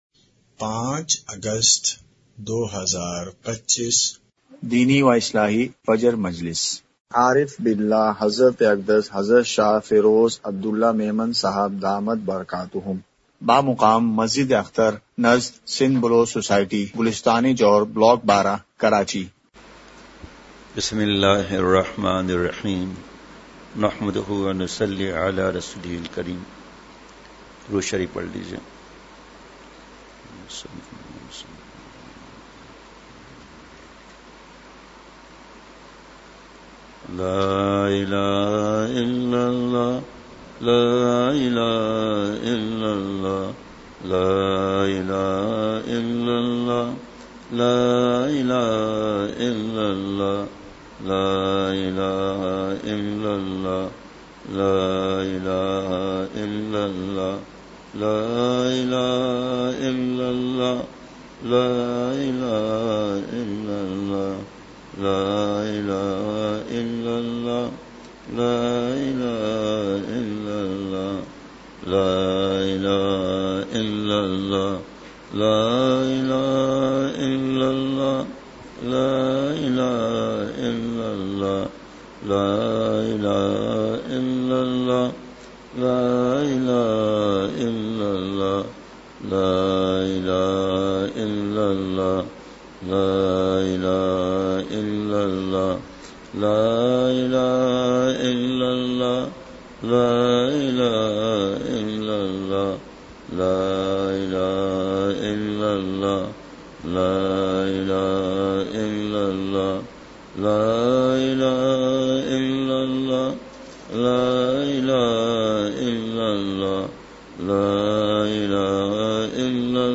مقام:مسجد اختر نزد سندھ بلوچ سوسائٹی گلستانِ جوہر کراچی
مجلسِ ذکر:کلمہ طیّبہ کی ایک تسبیح!!